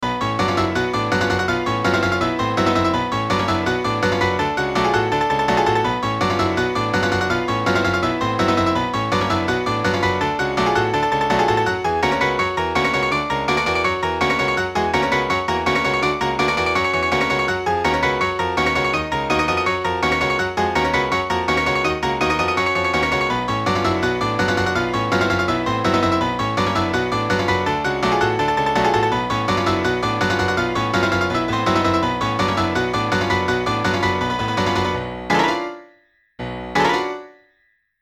原曲の荘厳な美しさを保ちつつ、ピアノの響きによって魔法のような雰囲気が演出されています。